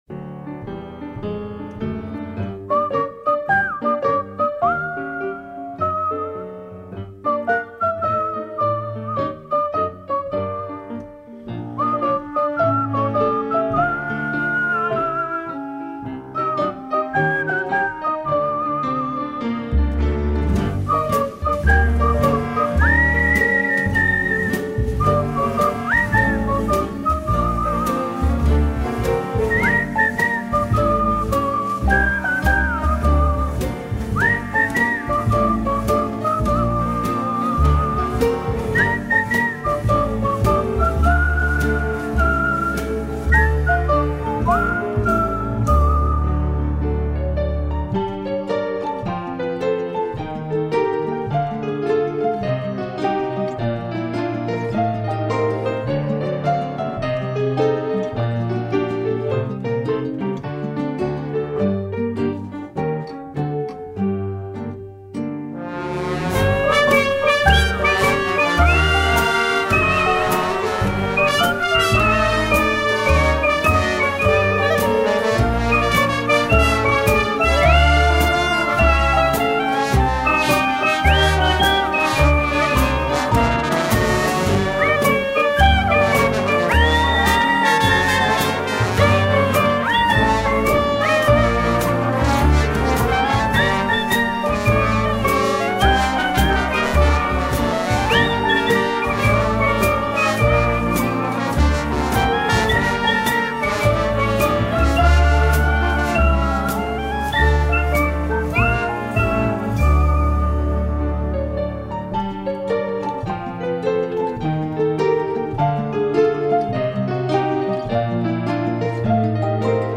οι τρεις ξεχωριστοί άνθρωποι και καλλιτέχνες με διακριτή διαδρομή μιλούν